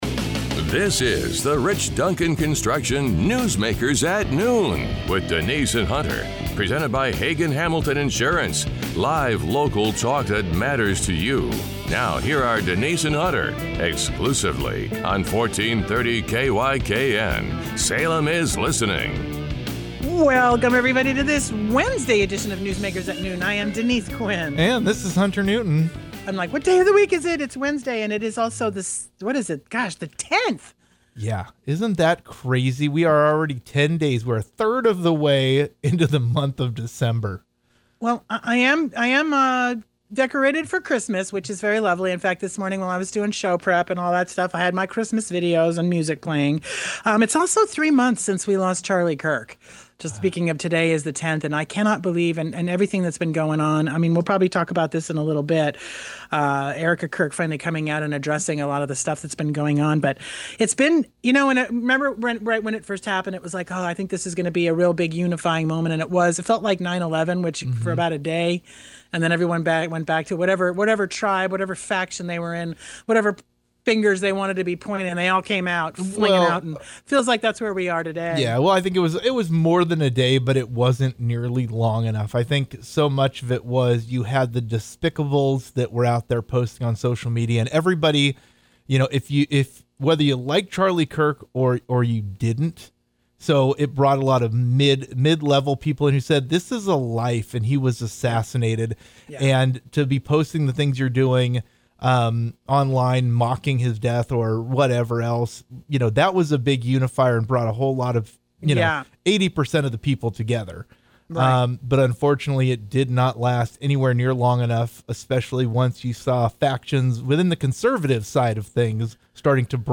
Guest Salem Mayor Julie Hoy